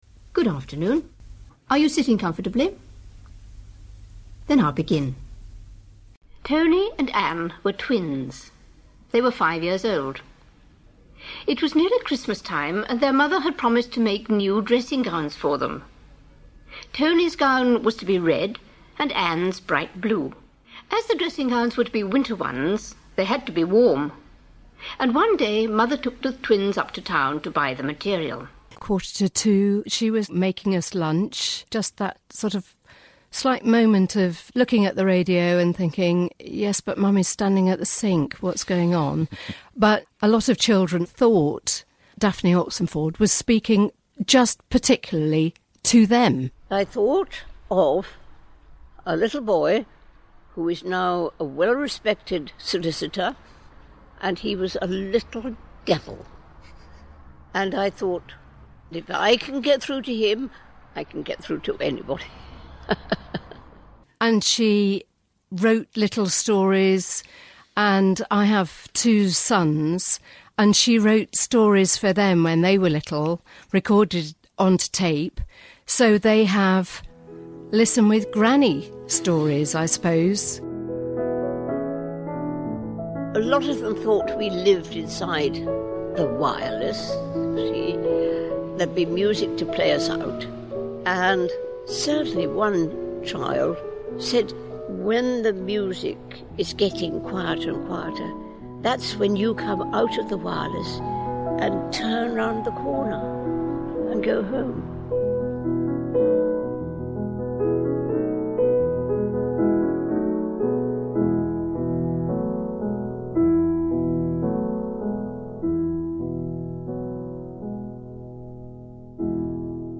On radio, her voice, redolent of an era, was heard in ‘The Clitheroe Kid’. From 1950 to 1971, she appeared on ‘Listen with Mother’, telling stories which kept her young audience seen and not heard.